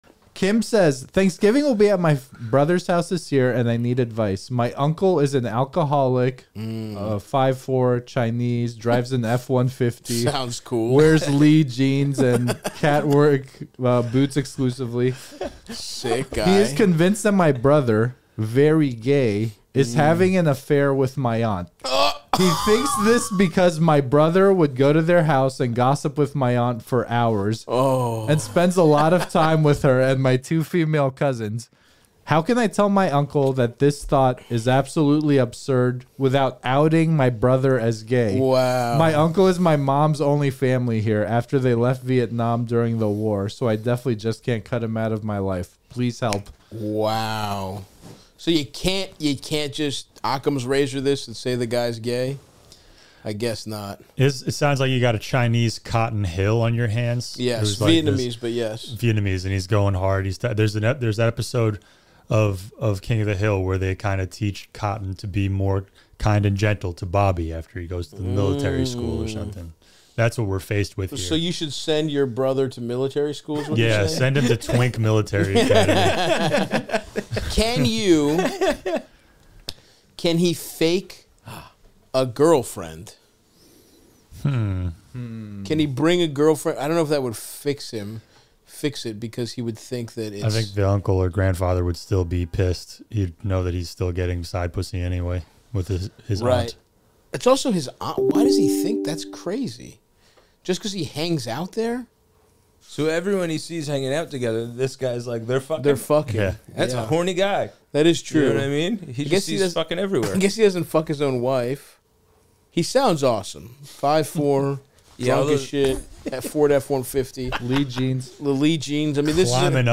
The boys look up election night search terms, take Patreon Thanksgiving-themed questions, and help callers including a guy who wants to know how to navigate political convo at the Thanksgiving table, and a woman whose friend wrought disgusting revenge on his roommate.